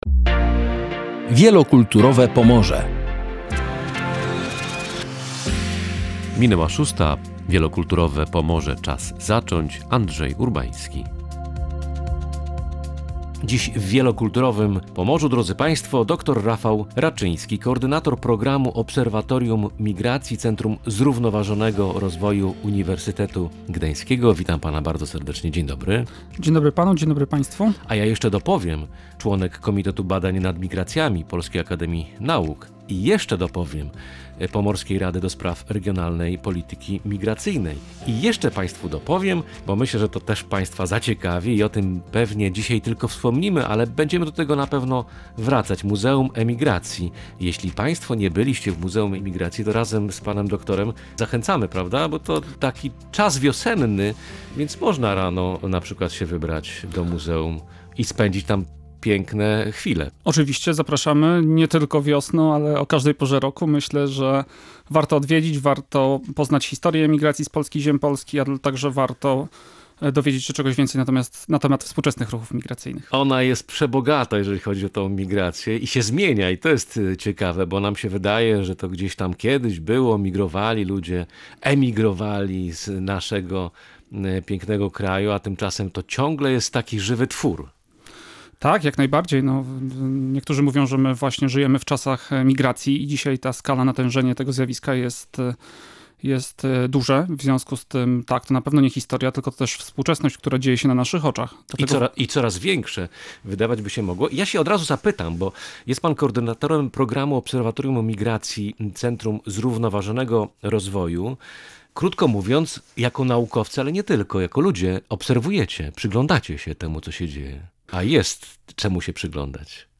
W ostatnich latach międzynarodowe migracje uznano za jeden z najważniejszych procesów społecznych, który silnie wpływa na sferę polityki, ekonomii oraz tożsamości kulturowej. W „Wielokulturowym Pomorzu” rozmawialiśmy właśnie o tym zjawisku.